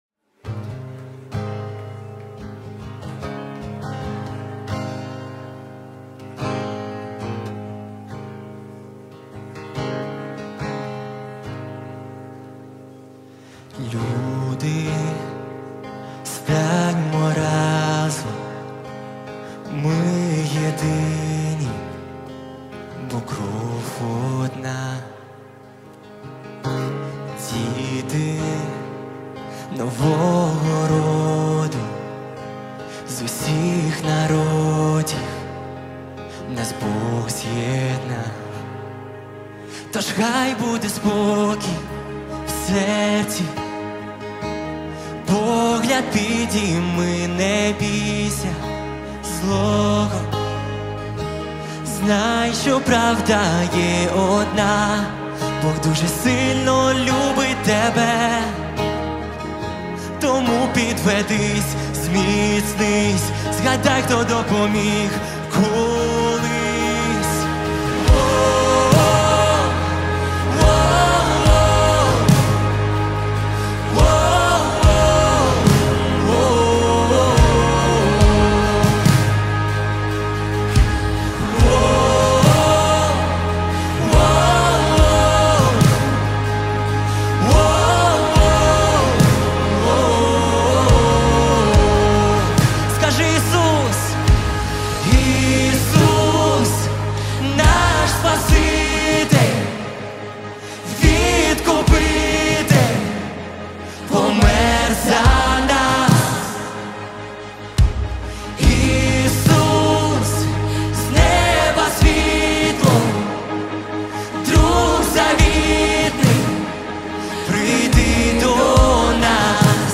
1490 просмотров 137 прослушиваний 32 скачивания BPM: 142